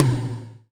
BIG HI TOM.wav